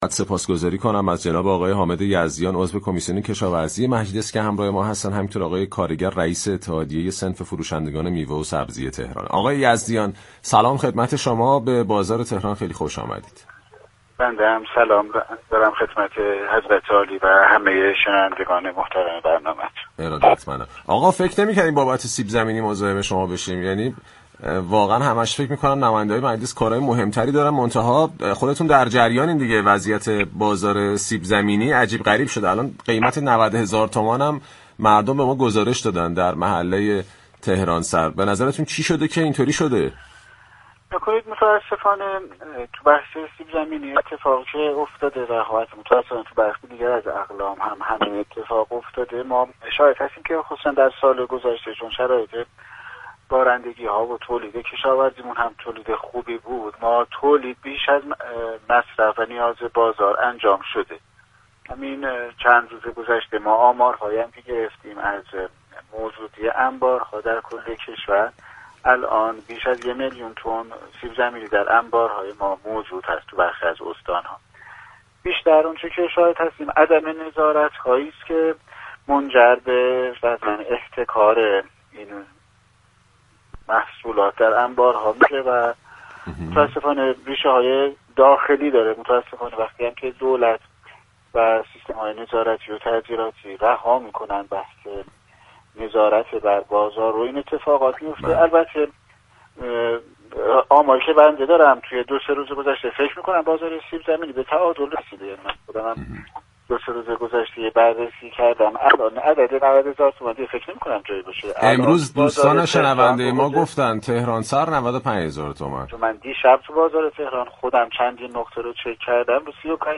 به گزارش پایگاه اطلاع رسانی رادیو تهران، حامد یزدیان عضو هیات رئیسه كمیسیون كشاورزی مجلس شورای اسلامی در گفت و گو با «بازار تهران» درخصوص افزایش قیمت سیب زمینی اظهار داشت: